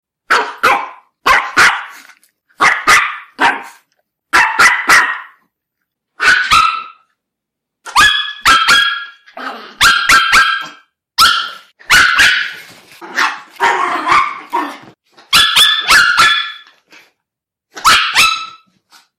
Growling Chihuahua Sound Button - Free Download & Play